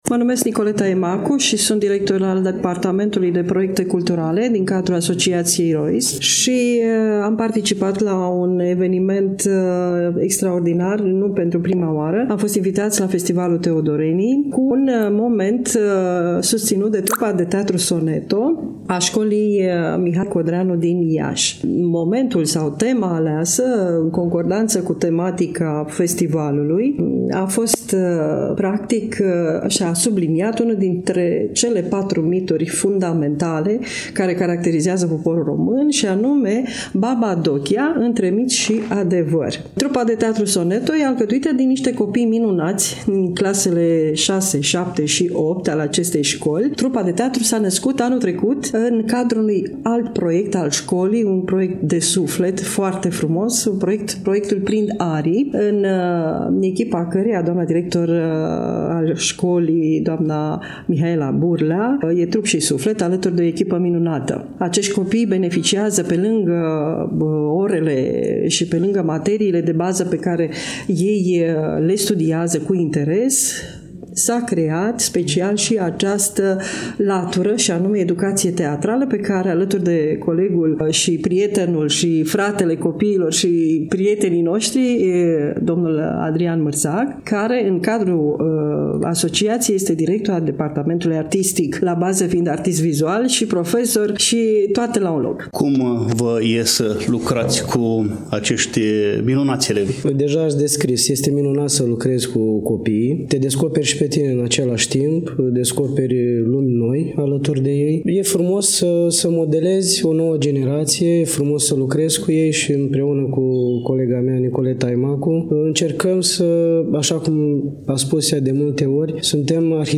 1_ROIS-Dialog-2-50.mp3